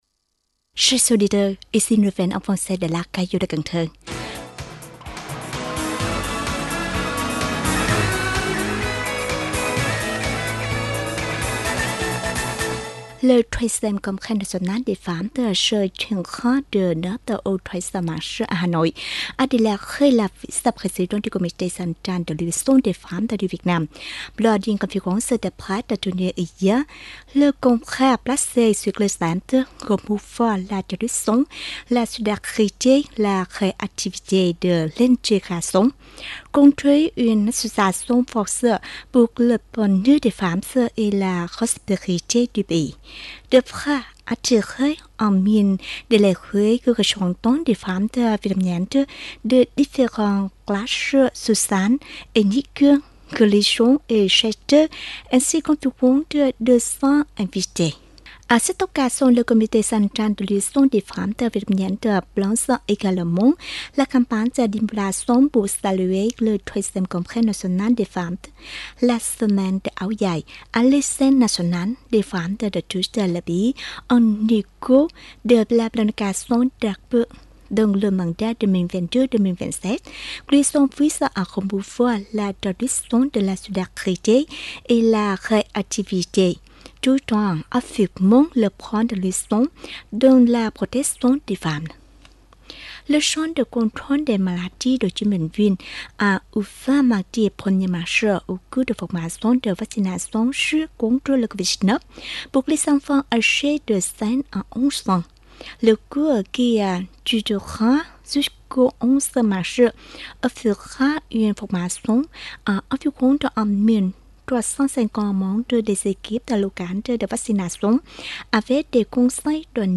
Bản tin tiếng Pháp 02/3/2022
Mời quý thính giả nghe Bản tin tiếng Pháp của Đài Phát thanh và Truyền hình thành phố Cần Thơ.